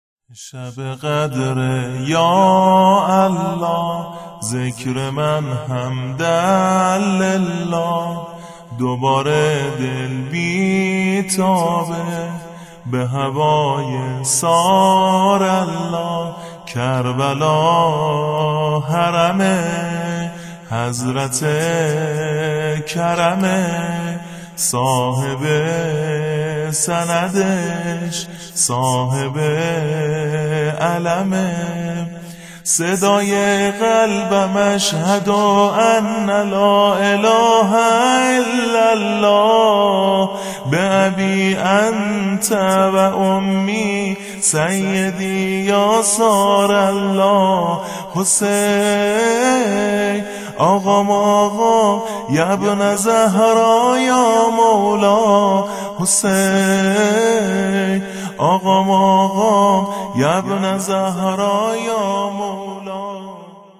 دانلود سبک